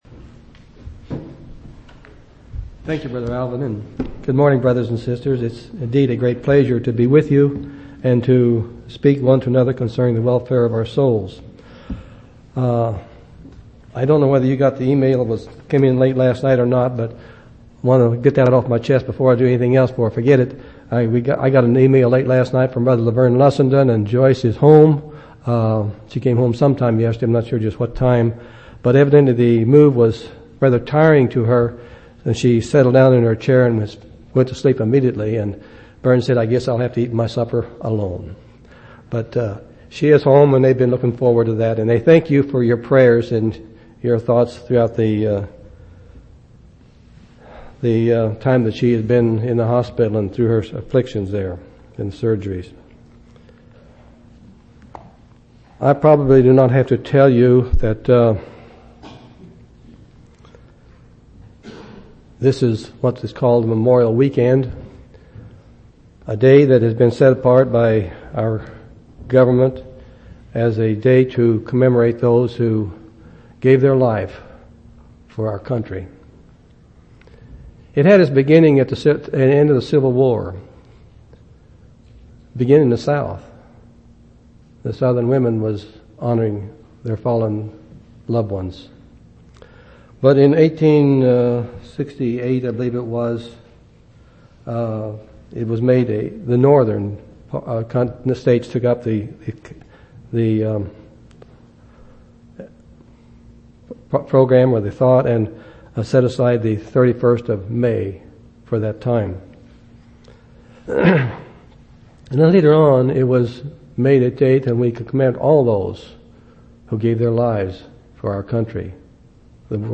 5/30/2004 Location: Temple Lot Local Event